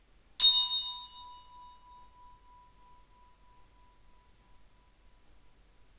Bell 03
bell bing brass ding sound effect free sound royalty free Sound Effects